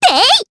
Annette-Vox_Attack2_jp.wav